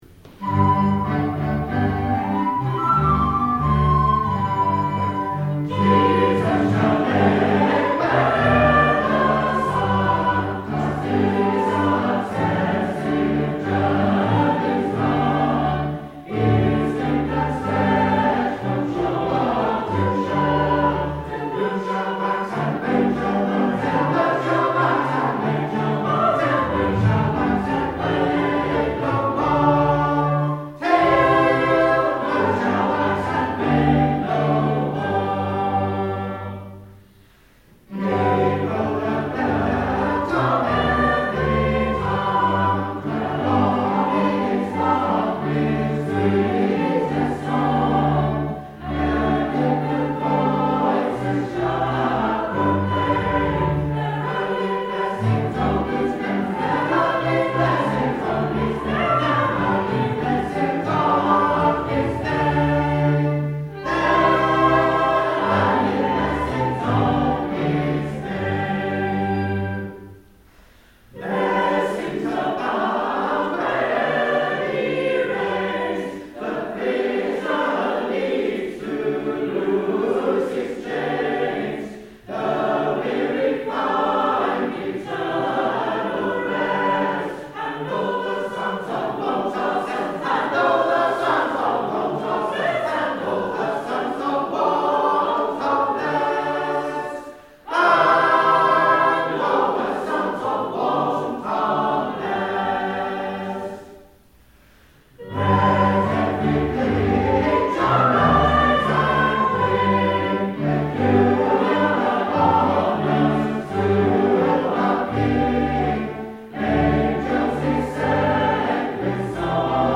Jesus shall reign where e'er the Sun (Gibraltar) performed by With Cheerful Voice
This wonderfully bouncy rendition of a West Gallery setting by W. J. White